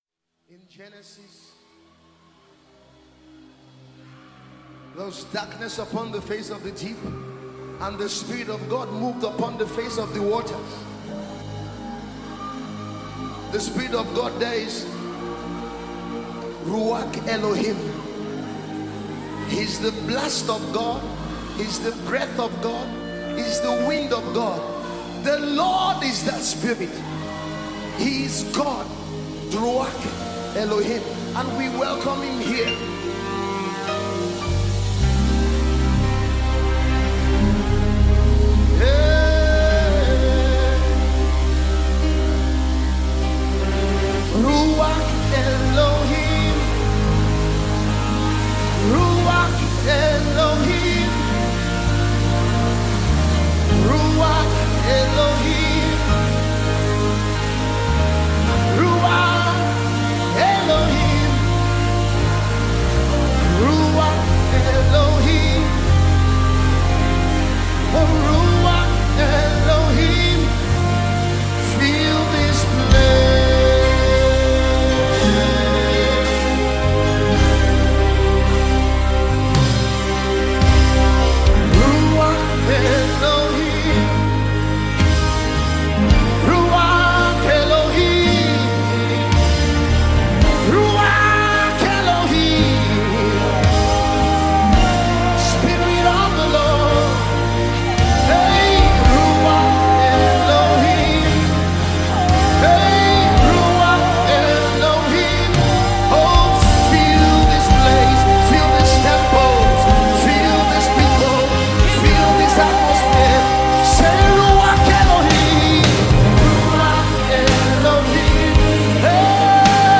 Nigerian gospel